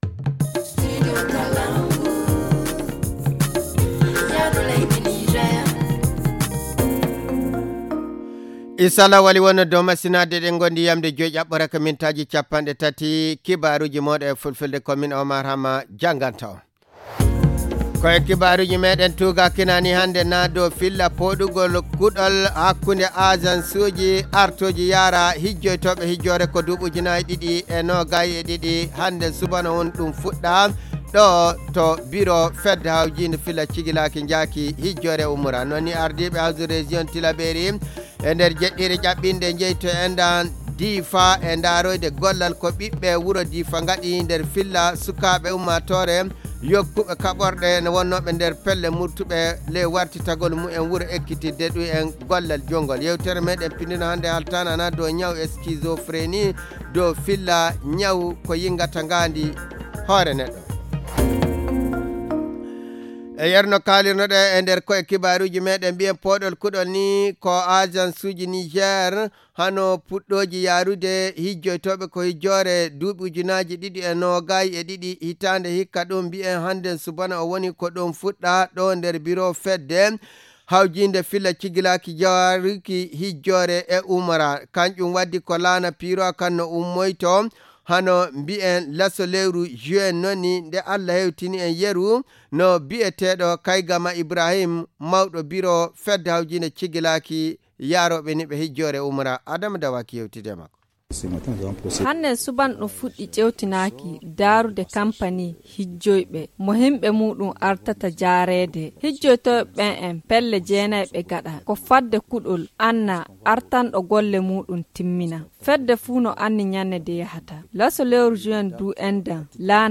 Le journal du 15 juin 2022 - Studio Kalangou - Au rythme du Niger